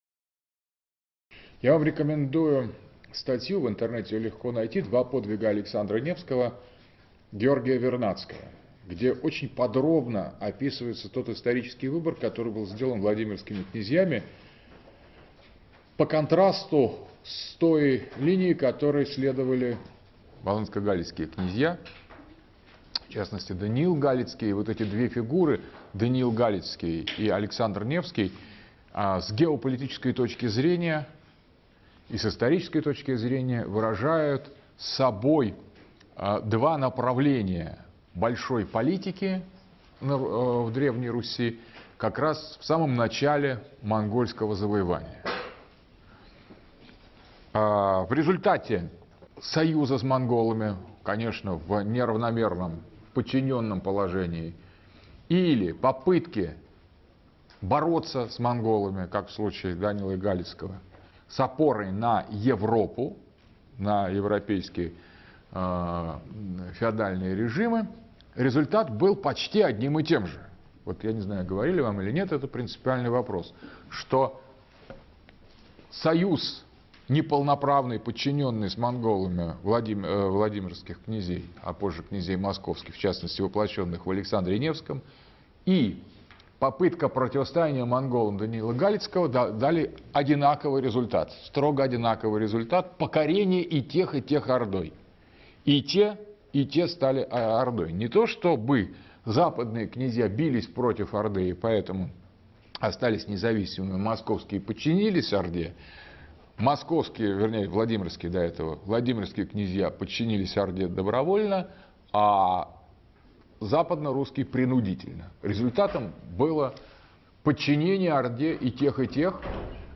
Читает А.Г. Дугин. Москва, МГУ, 2012.